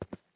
ES_Footsteps Concrete 9.wav